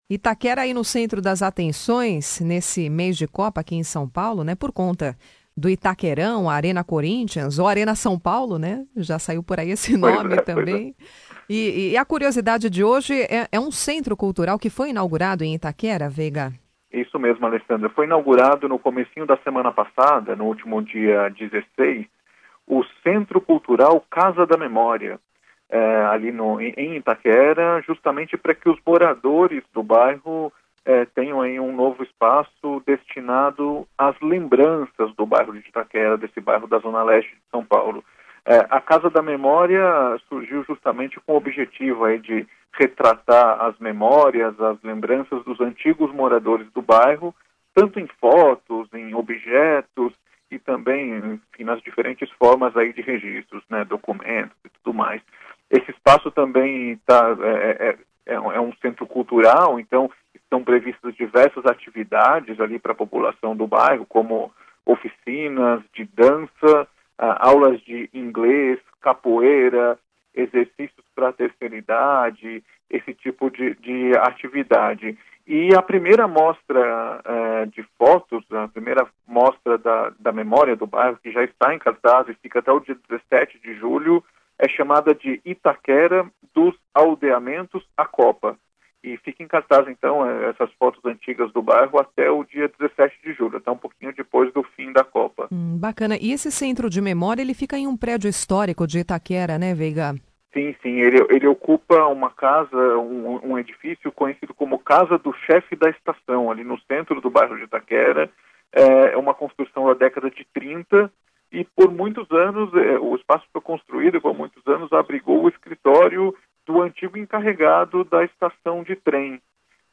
Desde junho, ali funciona a Casa da Memória de Itaquera (mais informações neste link). Clique no player abaixo para ouvir coluna sobre o tema, veiculada pela rádio Estadão: